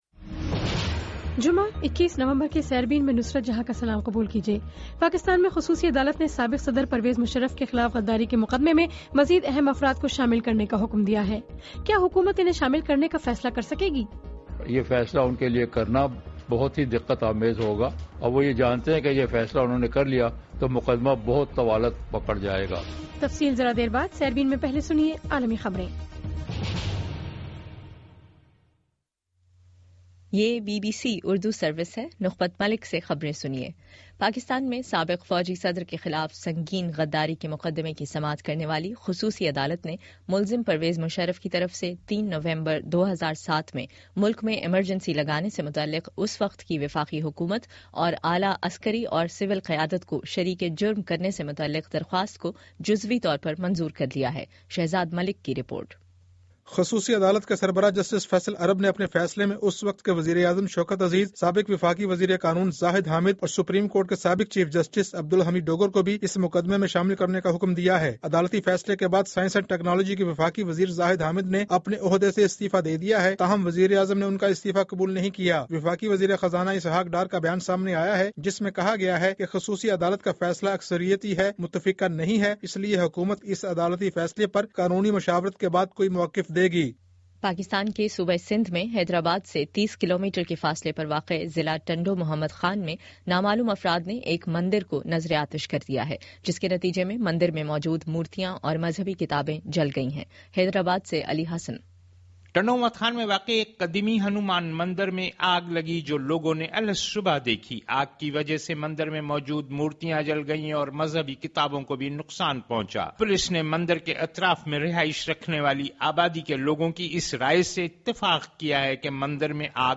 جمعہ 21 نومبر کا سیربین ریڈیو پروگرام